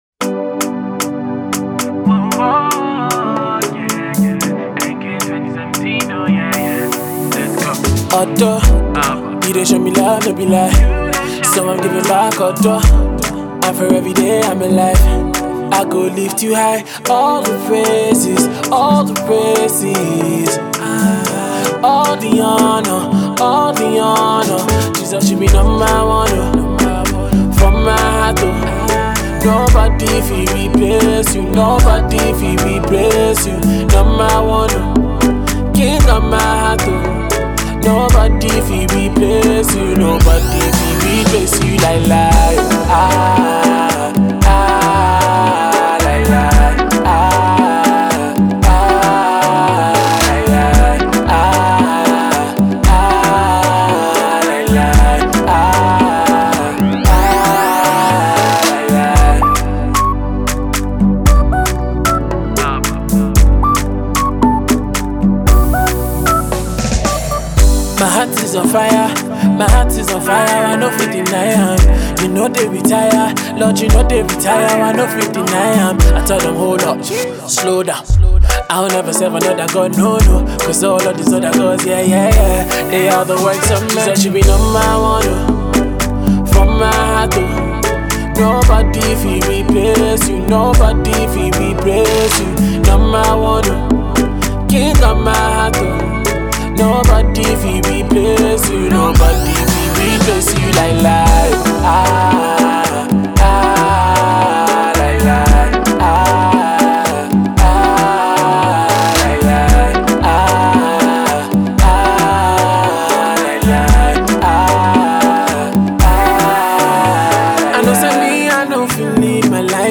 Soothing vibes and wholehearted worship